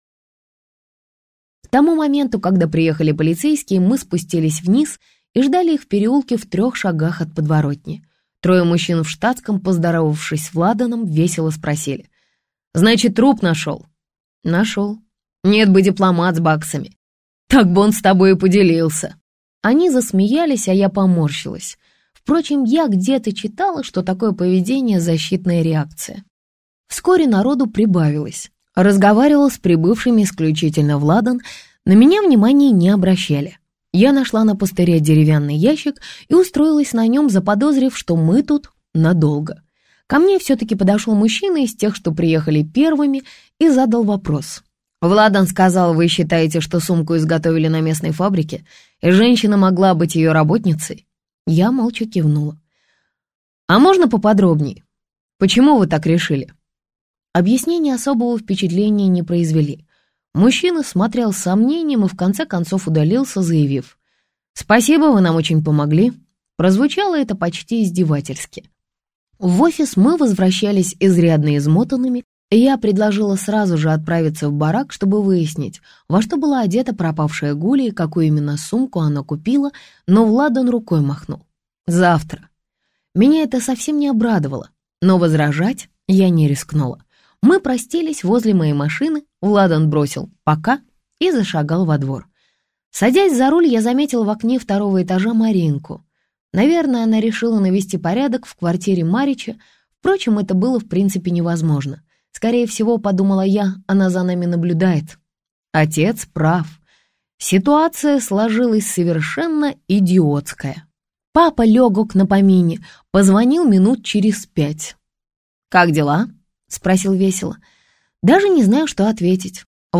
Аудиокнига Не вороши осиное гнездо - купить, скачать и слушать онлайн | КнигоПоиск